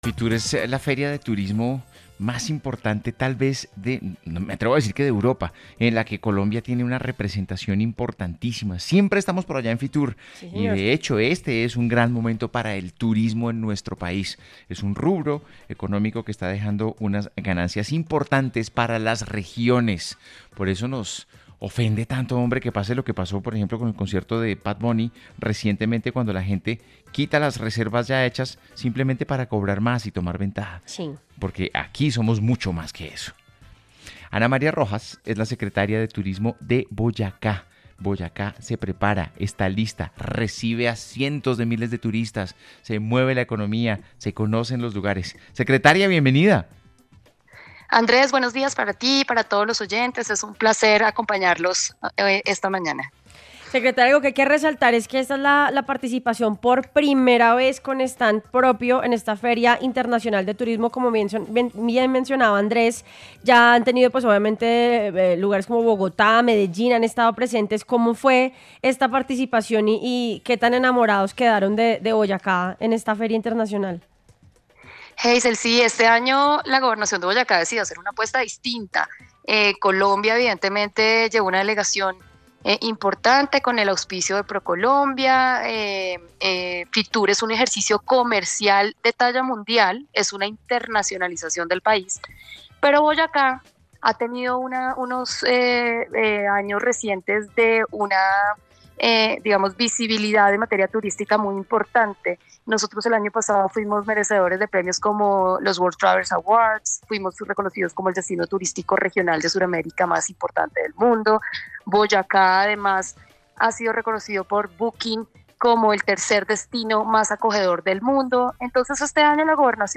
En A Vivir Que Son Dos Días, la secretaria de Turismo de Boyacá, Ana María Rojas, explicó que esta apuesta busca fortalecer el desarrollo económico del departamento a través del turismo, aprovechando el buen momento que atraviesa el país en esta industria.